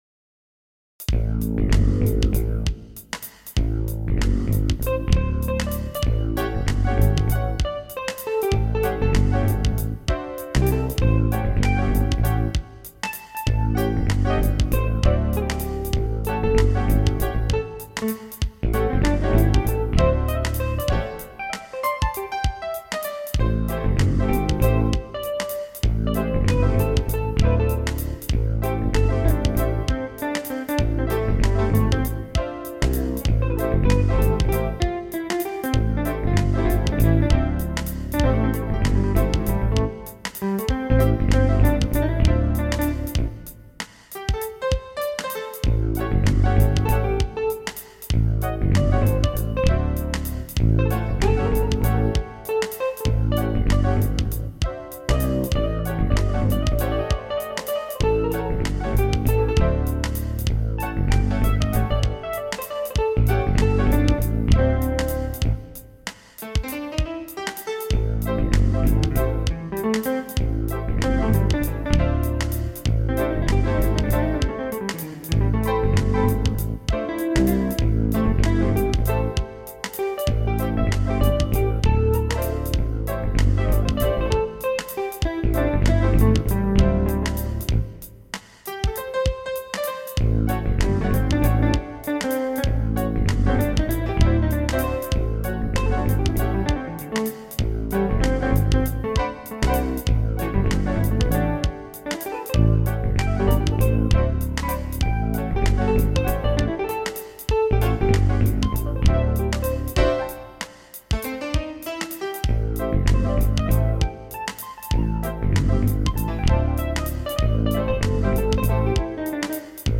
gothic, folk and rock
Recorded digitally, using a Sound Blaster Audigy 2 SE.